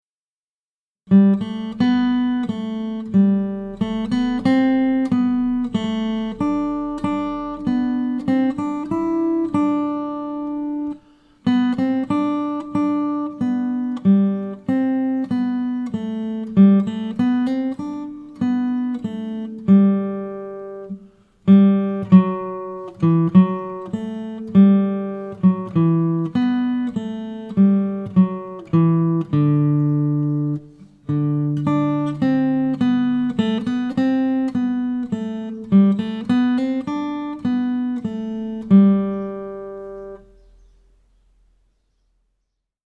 DIGITAL SHEET MUSIC - FINGERPICKING SOLO